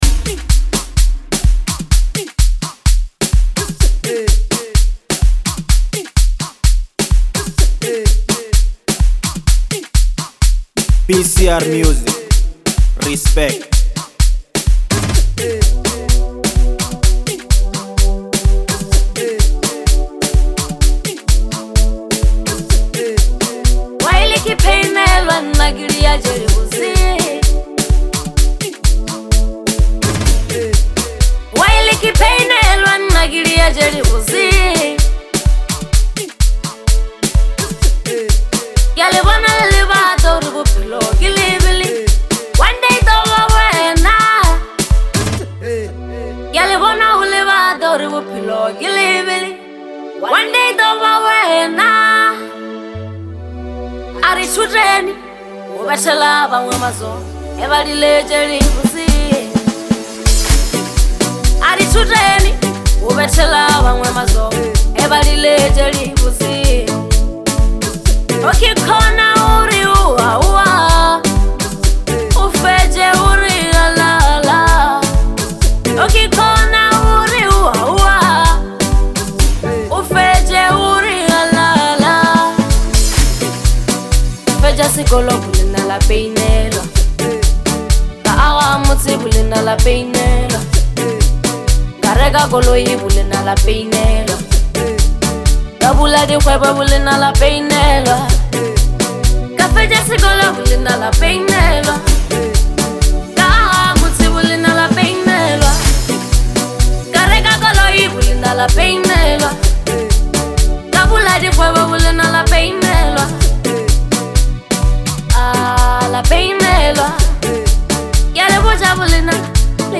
With her signature vocals and captivating sound